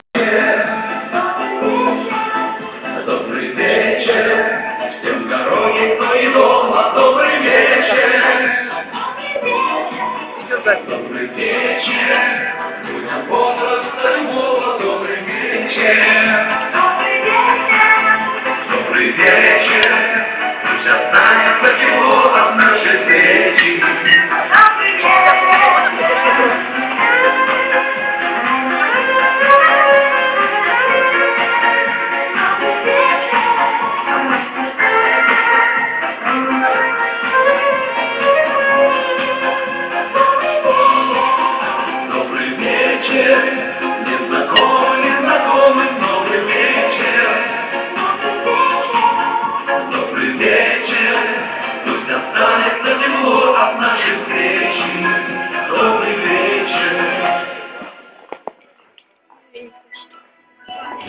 Всем Привет! Друзья обращаюсь с просьбой В свое время я записал на мобильный телефон песню на вечере в ДК санатория Хорол г.Миргород Полтавской обл Исполнял ВИА этого же ДК Может у кого есть эта песня в в каком либо другом исполнении Или может ктото знает что нибудь об этой песне Поговорить с исполнителем этой песни мне тогда не удалось